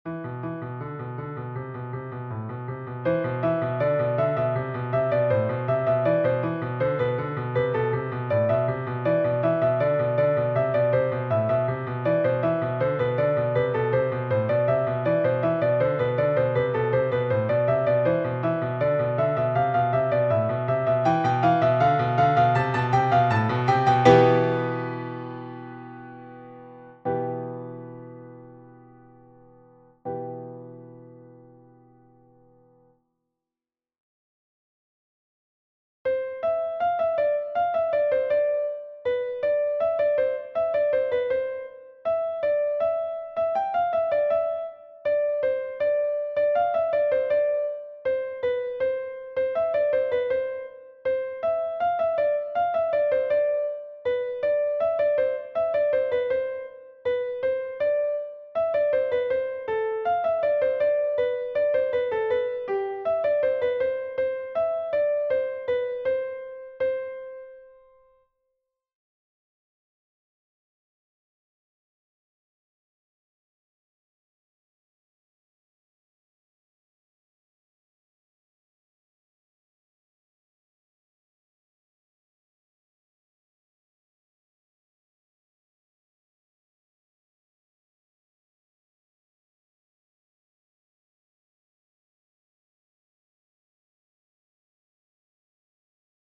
A Caprice I Composed - Piano Music, Solo Keyboard
It's short, and that's because I didn't know how to lengthen it much more.